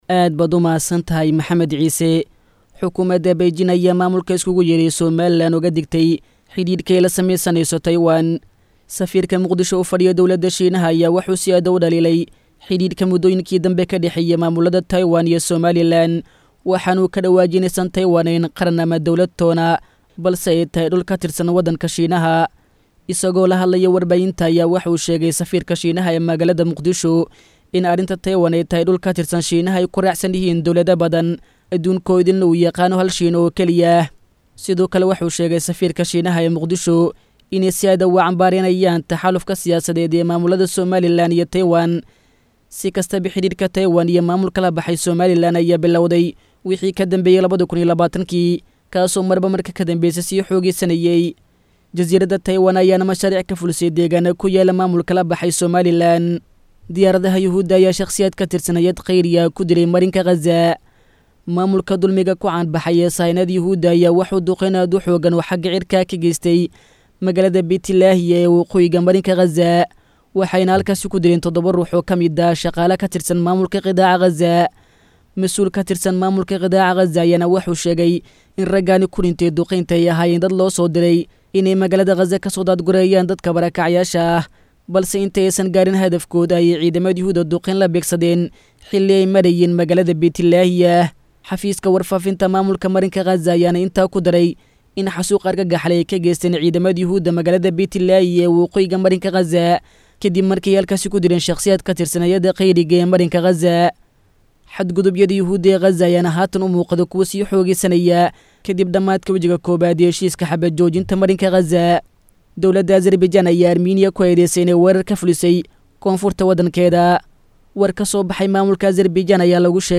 Xubinta Wararka Caalamka oo ku baxda Barnaamijka Dhuuxa Wareysiyada ee idaacadda Islaamiga ah ee Al-Furqaan, waxaa lagu soo gudbiyaa wararkii ugu dambeeyay ee daafaha Caalamka.